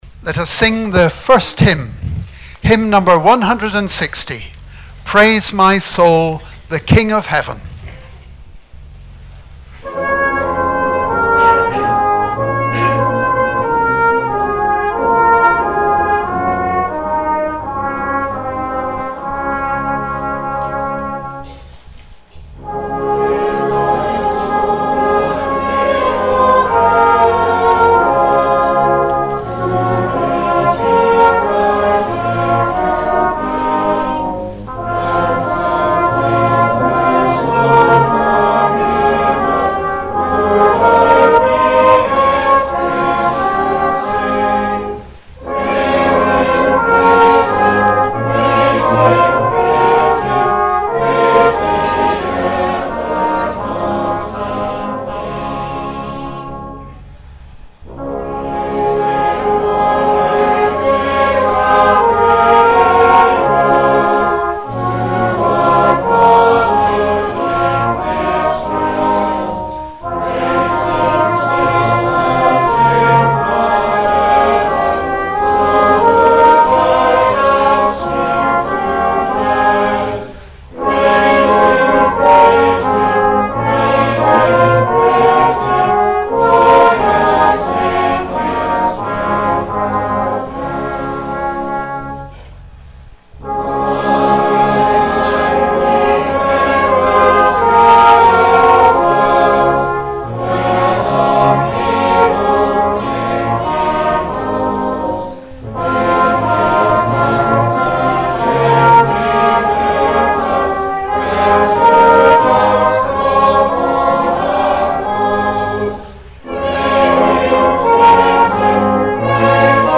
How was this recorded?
Remembrance Sunday - 10 November 2019